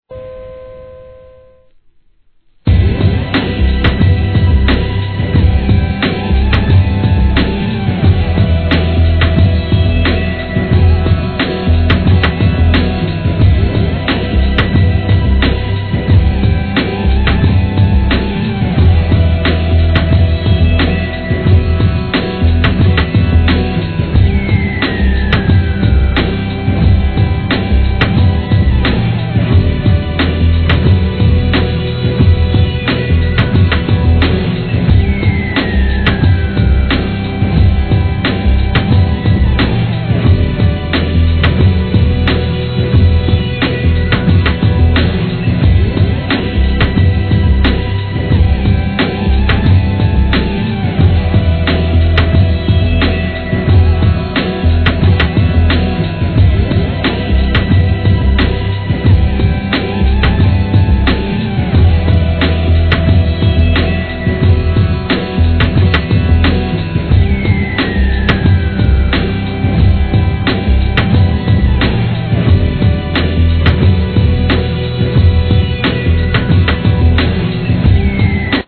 HIP HOP/R&B
エスニックでアブストラクトな音使いに壮大な世界が広がる人気ブレイクビーツ！